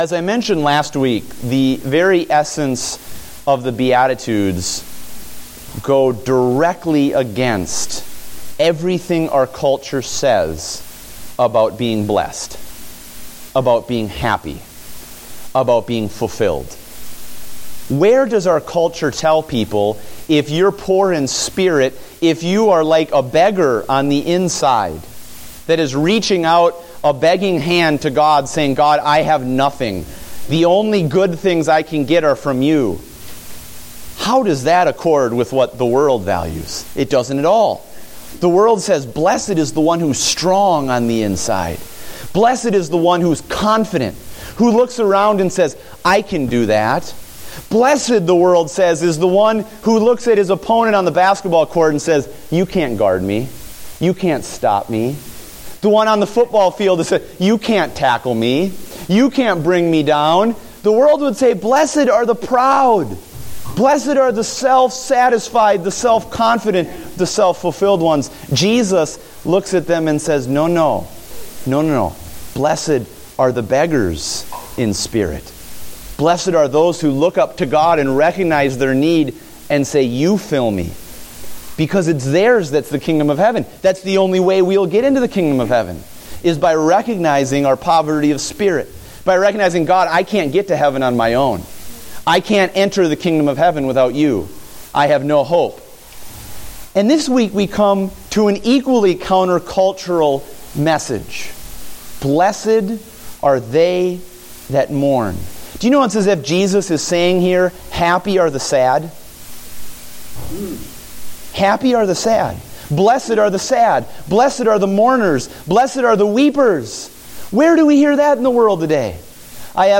Date: March 22, 2015 (Adult Sunday School)